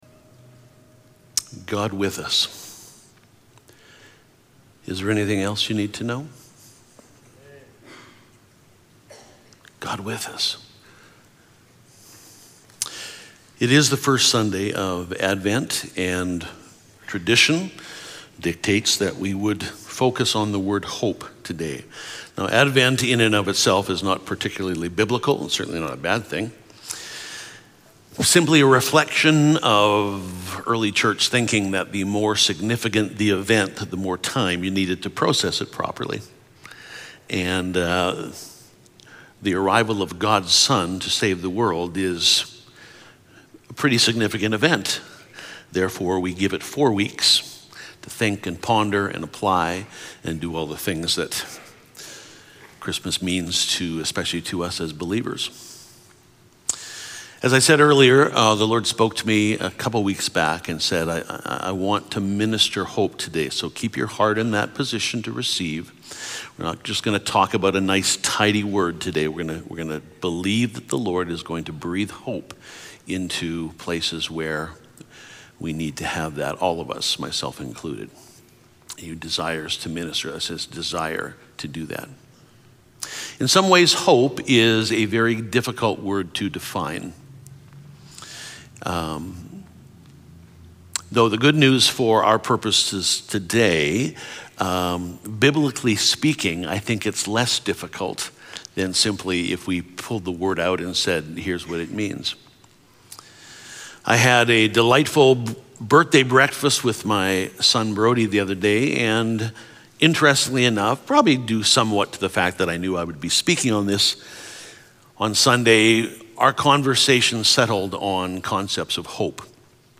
Sermons | Riverside Community Church